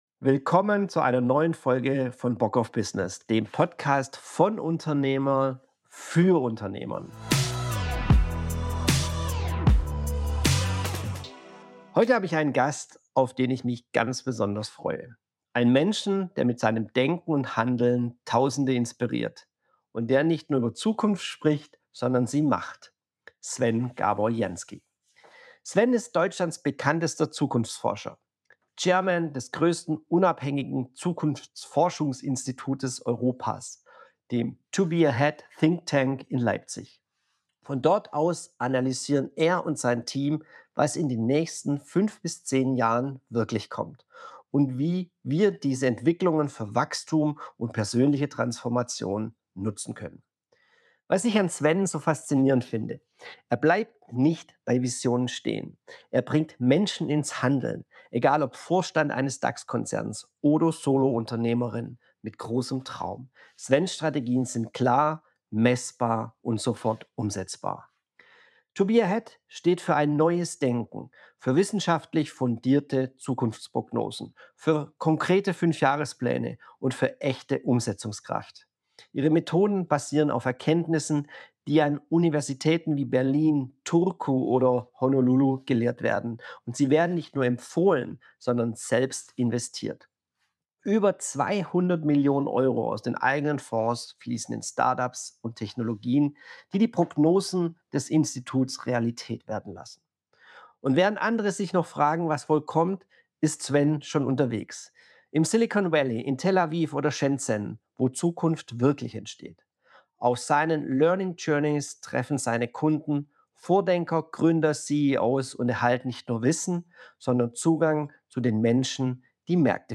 In der neuesten Folge von Bock auf Business spreche ich mit einem Mann, der nicht nur über Zukunft redet – sondern sie macht: Sven Gábor Jánszky, Deutschlands bekanntester Zukunftsforscher und Chairman des größten unabhängigen Zukunftsinstituts Europas – 2b AHEAD ThinkTank.